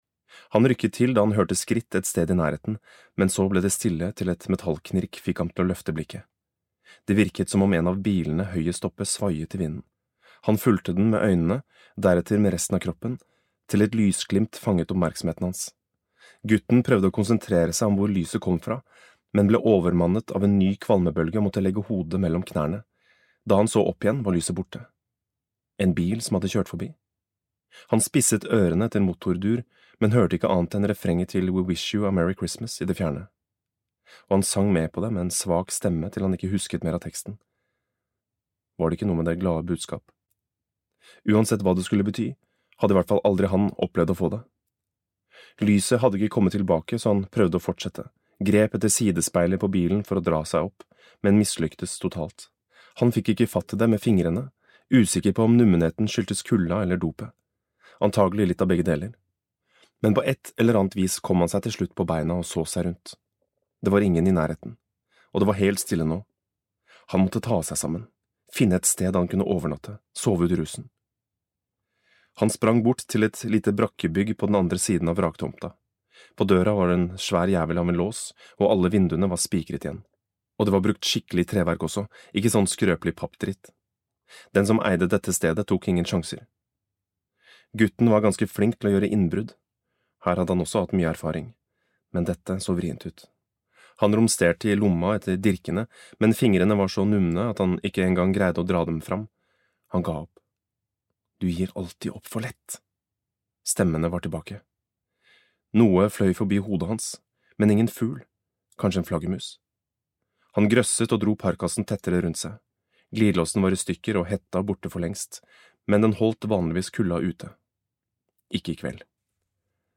Kryssild (lydbok) av Trevor Wood